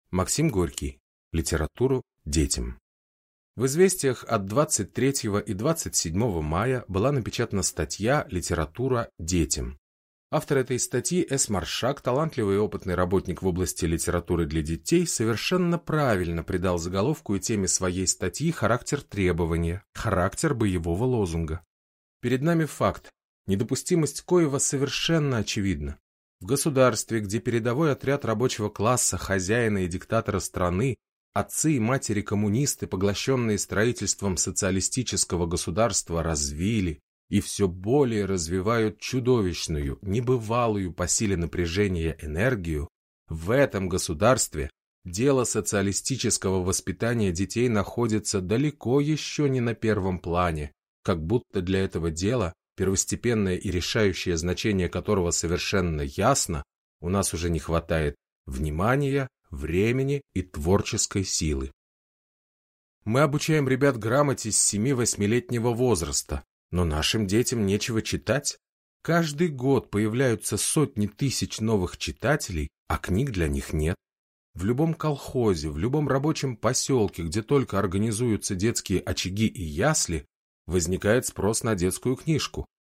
Аудиокнига Литературу – детям | Библиотека аудиокниг